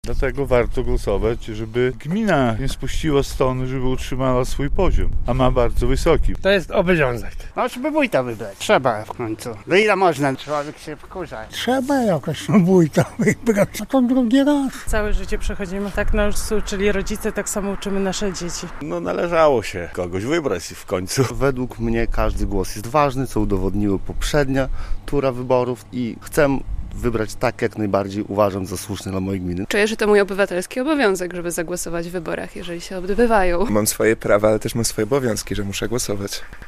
Mieszkańcy gminy Korycin wybierają wójta i podkreślają, że to ich obywatelski obowiązek - relacja